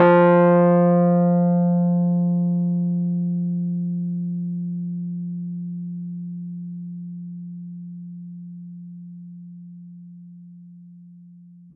Rhodes_MK1